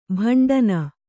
♪ bhaṇḍana